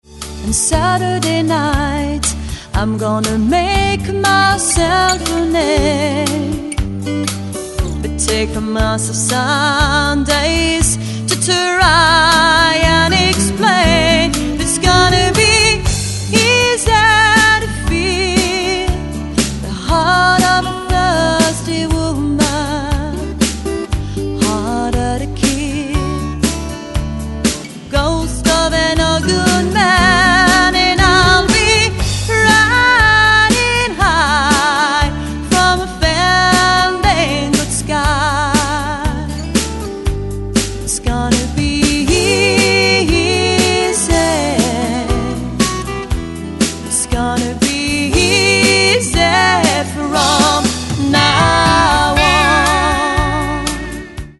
Chant, Harmonies
Basse, Programmation Batterie, Harmonies
Guitare Acoustique & Electrique, Programmation Batterie
Guitare Additionnelle